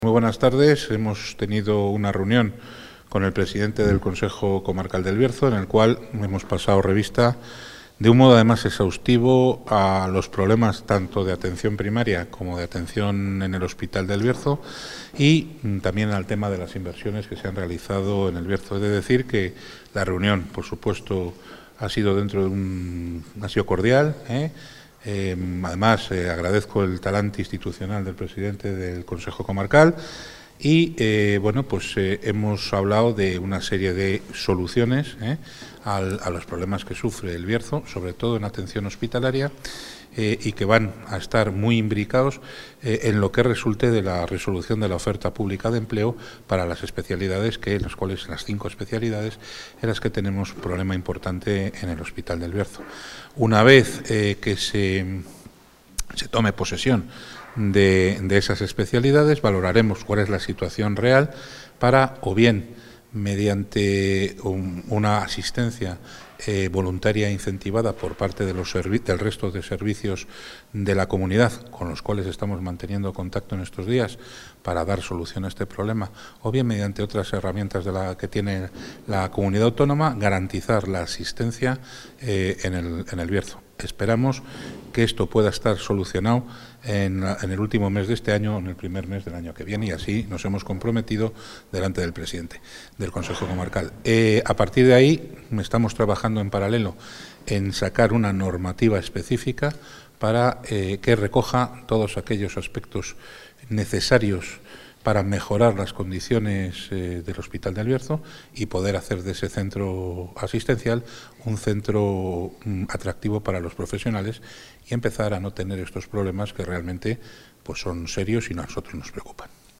Audio consejero.